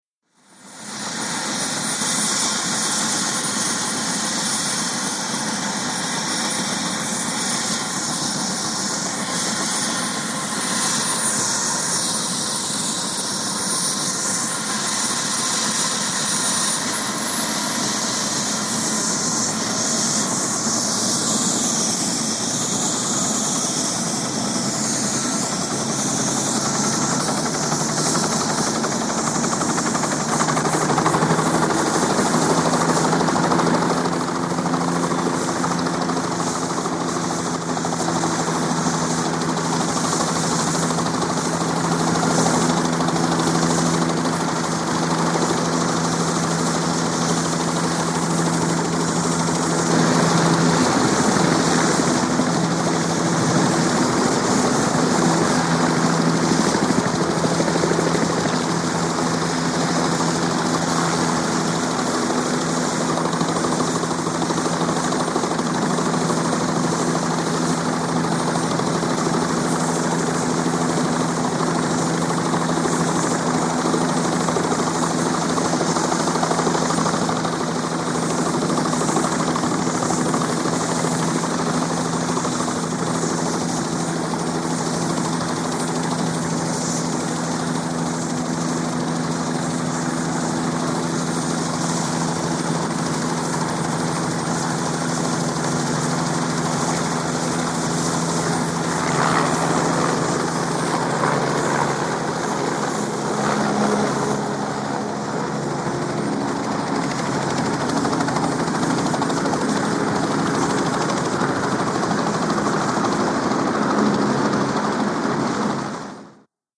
HELICOPTER JET: EXT / INT: Warm up, idle, takeoff, hover.